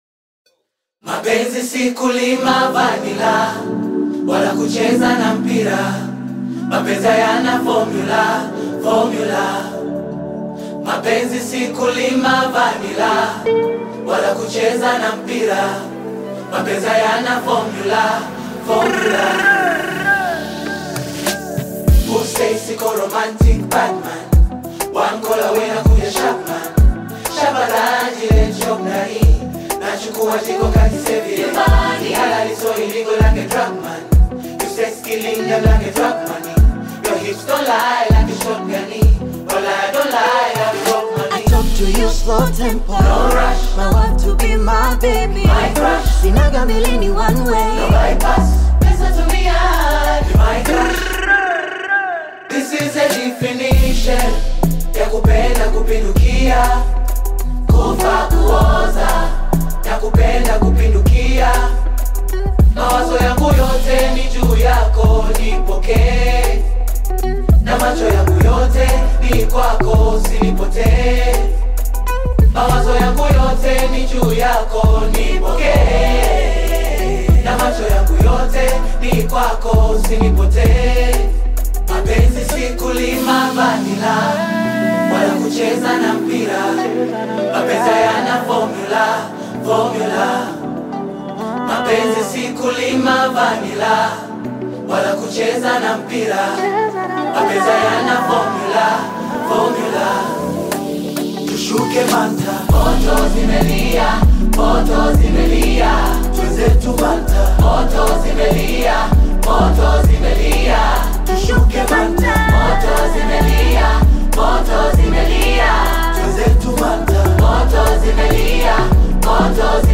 Tanzanian Bongo Flava Choir
This is a choir version of the song
Bongo Flava You may also like